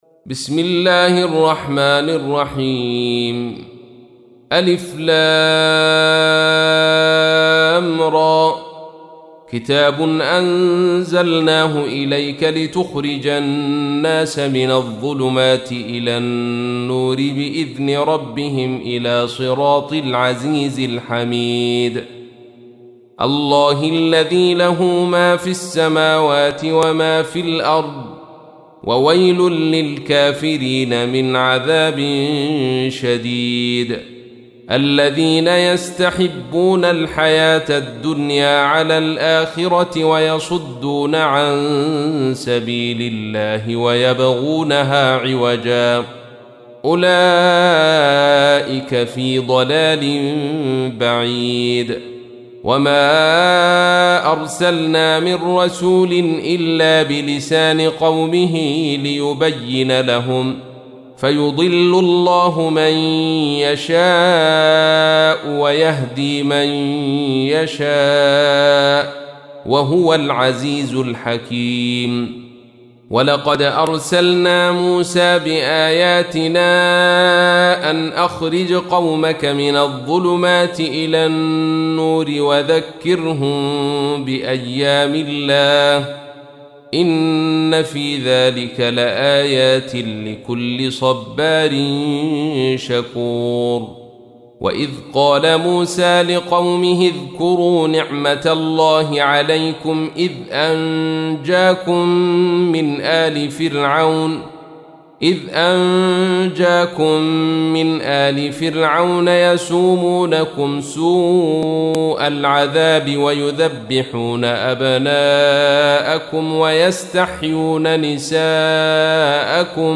تحميل : 14. سورة إبراهيم / القارئ عبد الرشيد صوفي / القرآن الكريم / موقع يا حسين